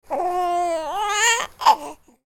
Baby Cooing Sound Effect
This cute baby coo sound effect captures the soft, gentle, and adorable sounds of a happy baby. Human sounds.
Cute-baby-coo-sound-effect.mp3